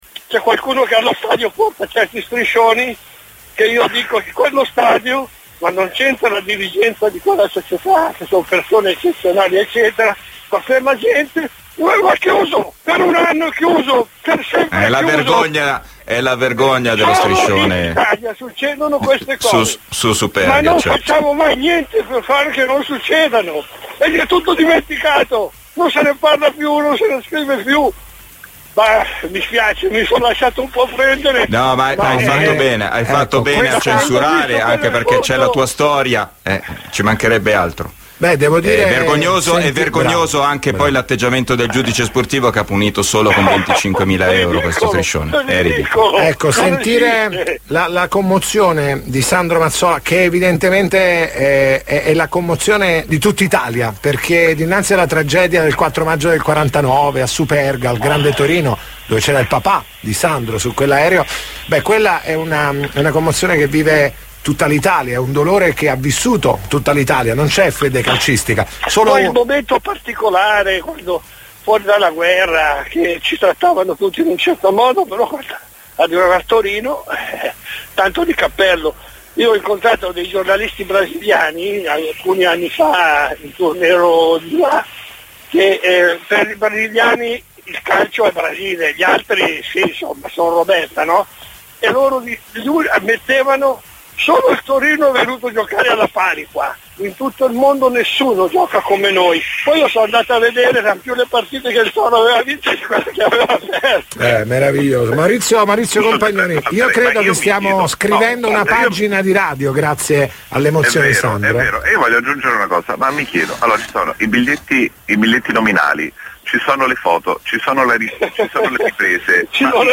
Ore 18:10-SPORT (CALCIO): Sandro Mazzola ( figlio dell'indimenticabile Valentino), scoppia in lacrime in diretta su Radio Kiss Kiss durante una trasmissione sportiva legata al ricordo della tragedia di Superga: